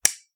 click-a.ogg